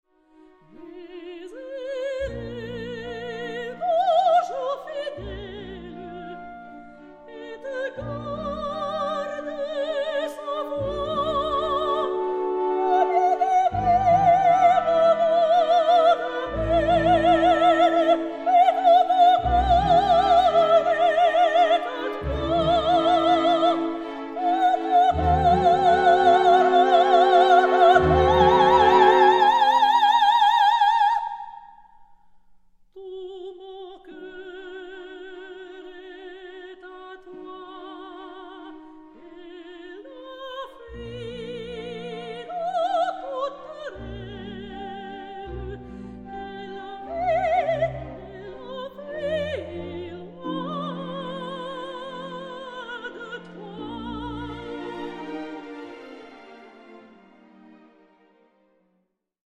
ravishing Australian soprano
Soprano